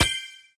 snd_success.ogg